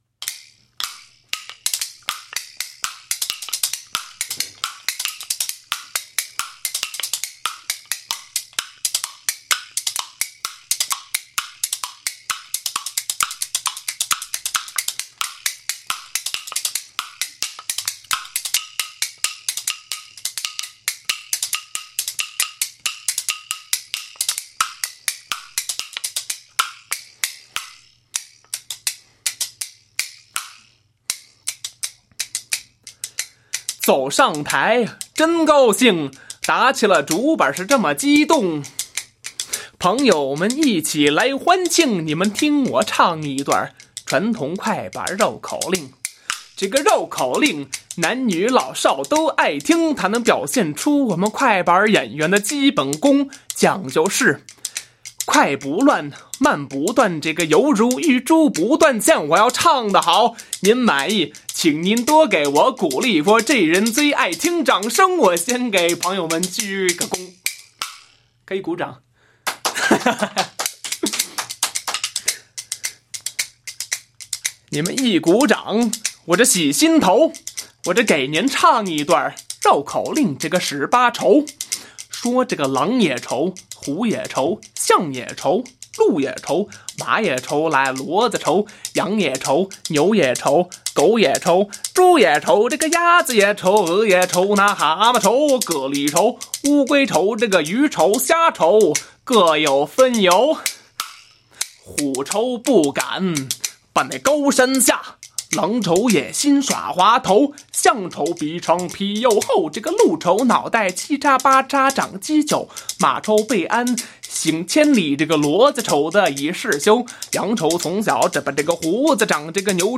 绝对正宗的快板！